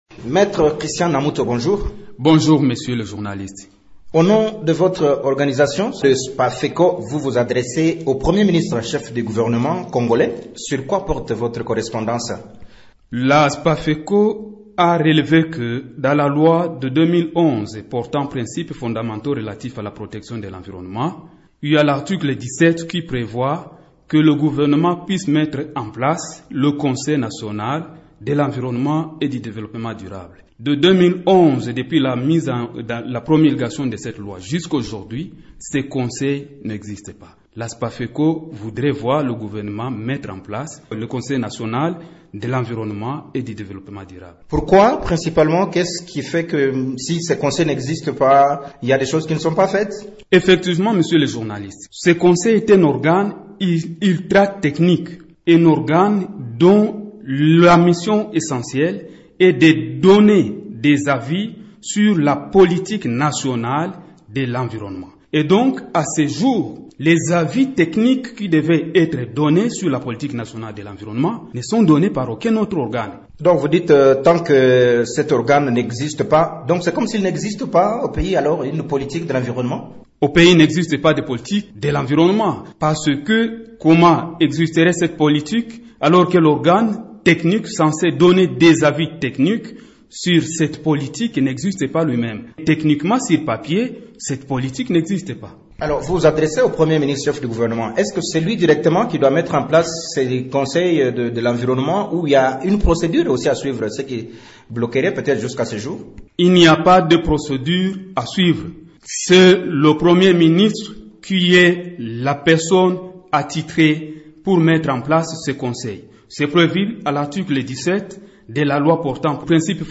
s’entretient avec